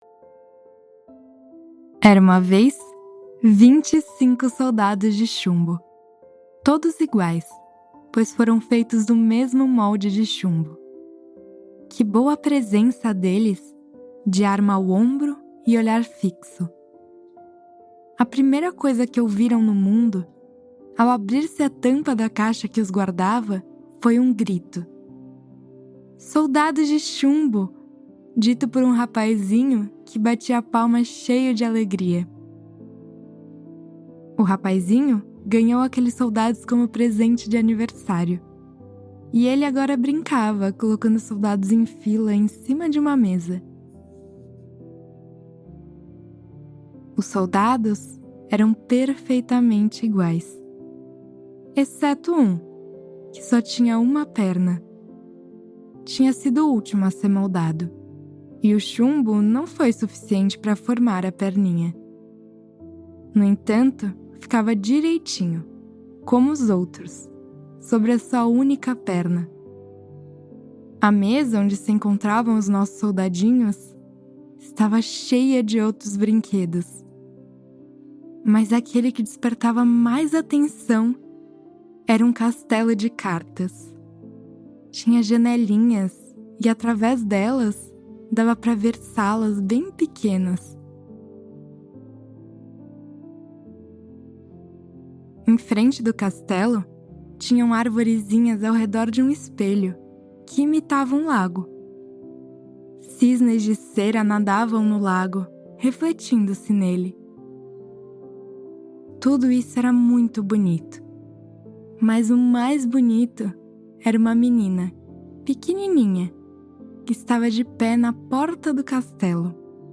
Schlafgeschichte – Der standhafte Zinnsoldat mit sanften Klängen zum Einschlafen